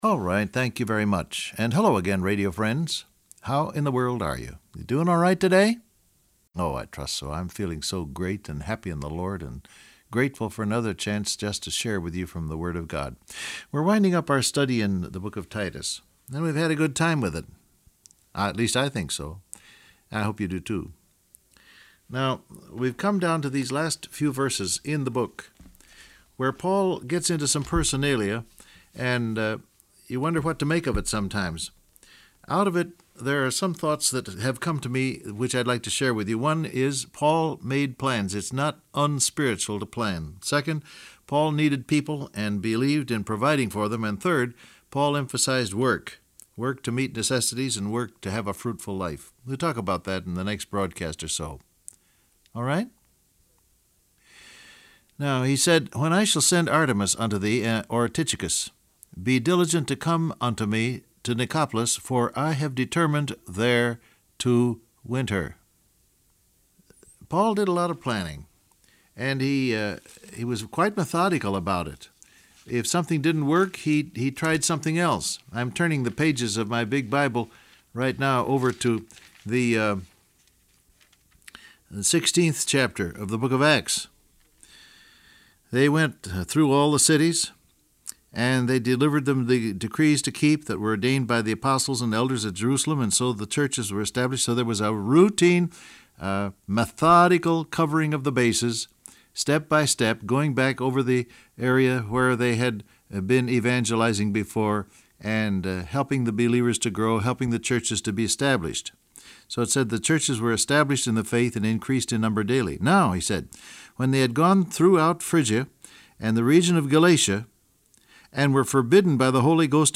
Download Audio Print Broadcast #6480 Scripture: Titus 3:12 , Acts 16 Topics: Planning , Innovation Transcript Facebook Twitter WhatsApp Alright thank you very much, and hello again radio friends.